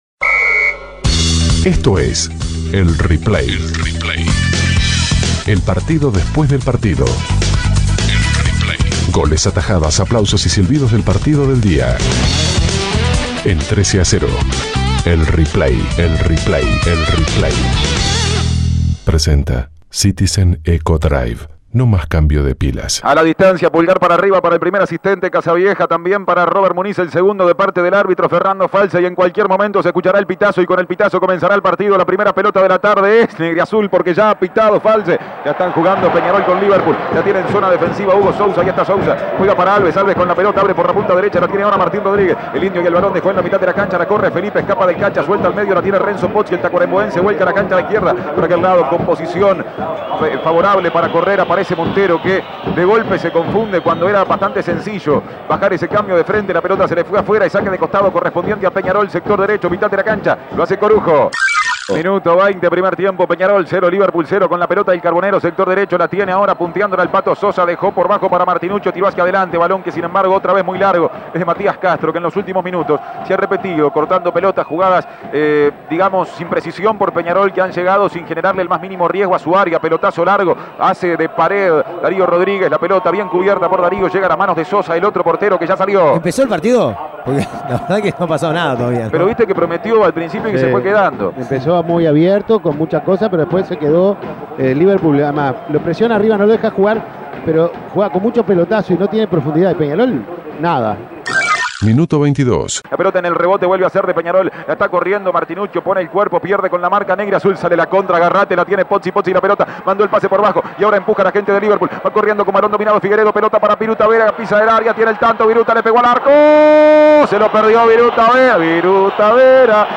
Goles y comentarios Escuche el replay de Peñarol - Liverpool Imprimir A- A A+ Liverpool venció por 2 a 1 a Peñarol por la décima fecha del torneo Apertura.